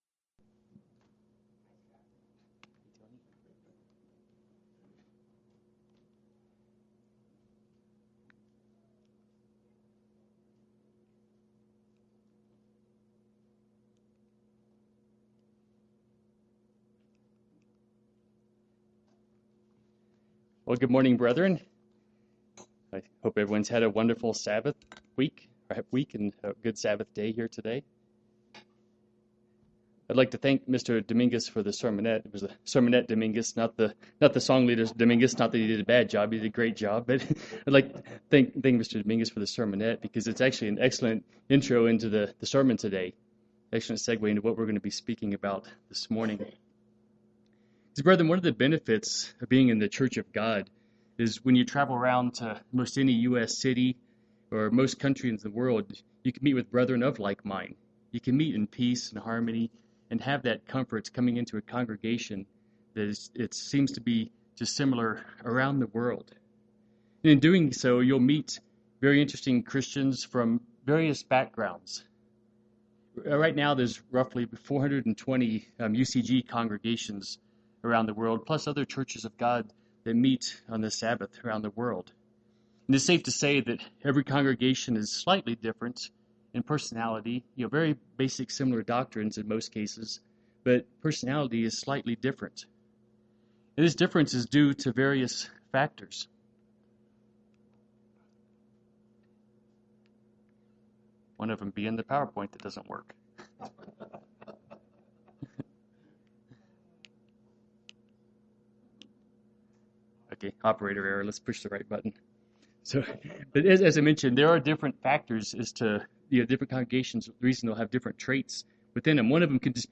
The 1st century churches of the Lycus River Valley in Asia Minor prospered financially but struggled spiritually. This sermon will look at traits from those churches and lessons for us today.